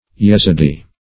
yezidee - definition of yezidee - synonyms, pronunciation, spelling from Free Dictionary Search Result for " yezidee" : The Collaborative International Dictionary of English v.0.48: Yezidee \Yez"i*dee\, Yezidi \Yez"i*di\, n. Same as Izedi.
yezidee.mp3